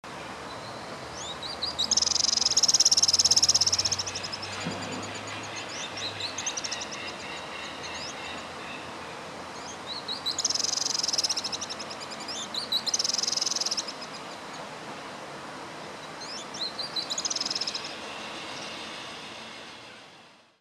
Remolinera Araucana (Cinclodes patagonicus) - EcoRegistros
Cinclodes patagonicus chilensis
Nombre en inglés: Dark-bellied Cinclodes
Fase de la vida: Adulto
Localidad o área protegida: San Martin de los Andes
Condición: Silvestre
Certeza: Vocalización Grabada